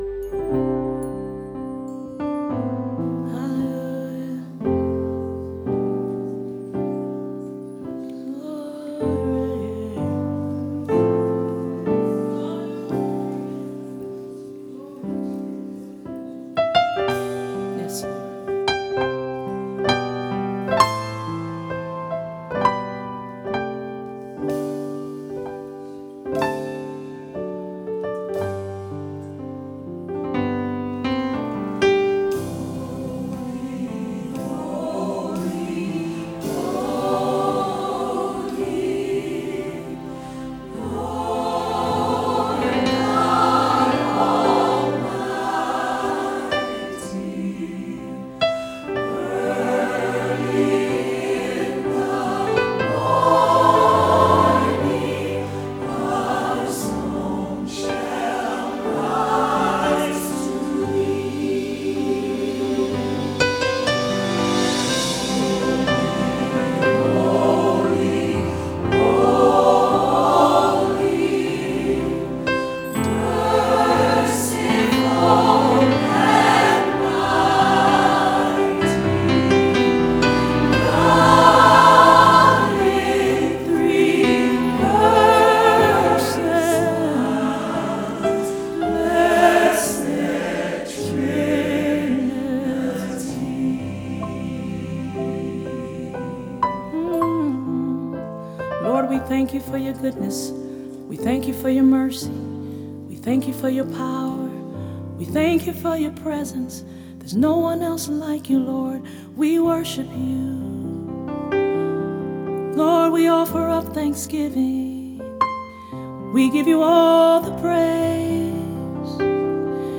장르: Funk / Soul, Pop
스타일: Gospel, Vocal